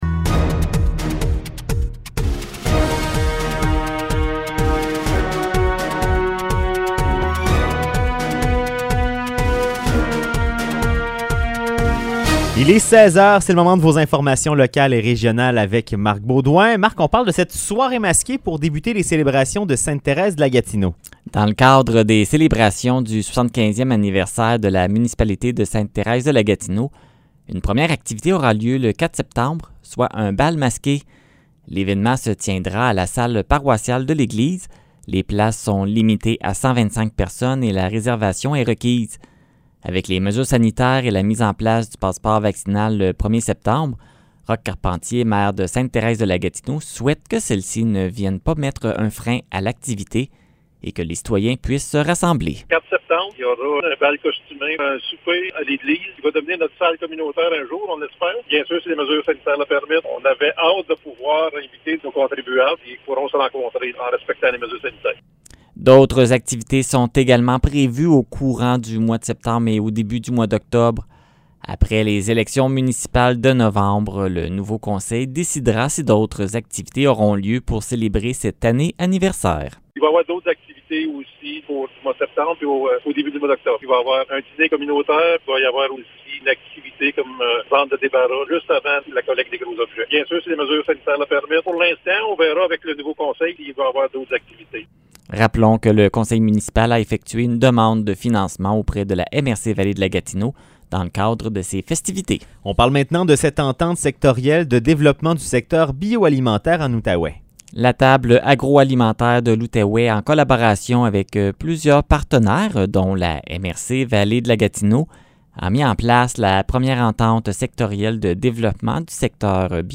Nouvelles locales - 11 août 2021 - 16 h